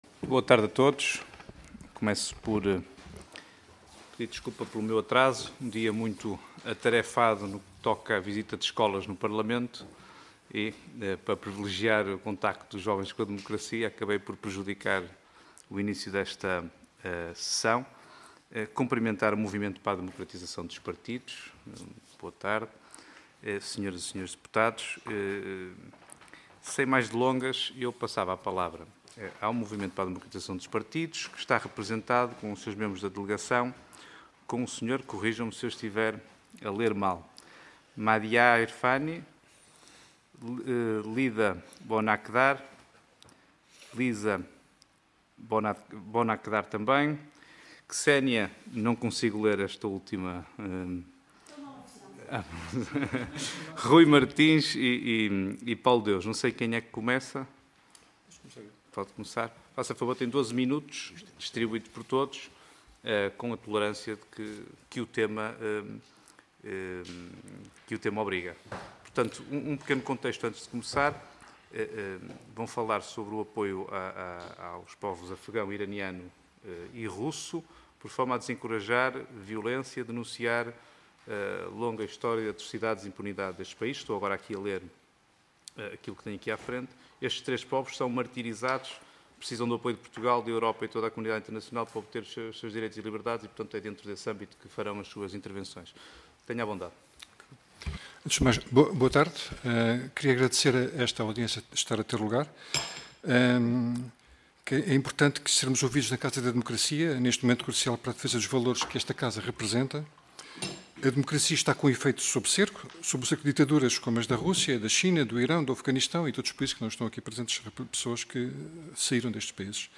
Comissão de Negócios Estrangeiros e Comunidades Portuguesas Audiência Parlamentar Nº 4-CNECP-XVI Assunto Apoio aos povos afegão, iraniano e russo, por forma a desencorajar mais violência e a denunciar a longa história de atrocidades e impunidade nestes países.